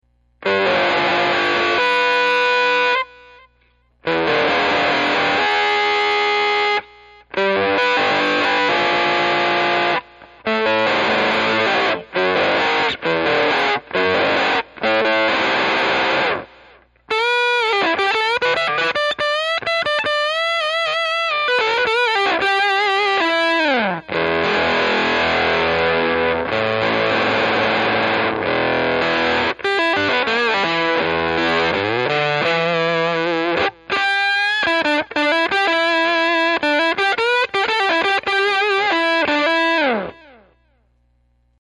First is Green Ringer solo, second is a ToneBender into Green Ringer.  Strat on neck/middle with a bit of delay.  Strat->homebrew Champ->Celestion Vintage 30->condenser close mic'd->mixer->soundcard.
The splatty early tones caused by the large amounts of harmonic intermodulation when 2 or more notes are played at the same time, and the Pronounced Octaves later in the clip, when less than 2 notes are the source.